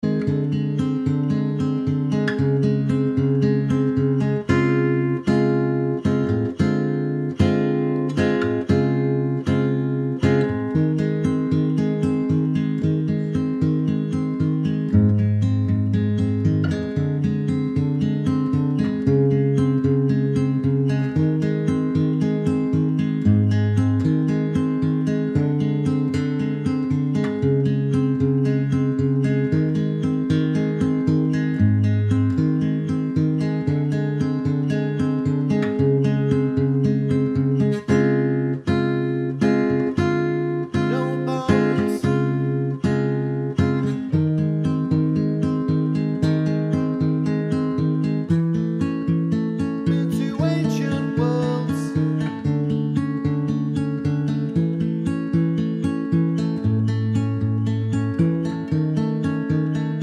no Backing Vocals Pop (1980s) 2:23 Buy £1.50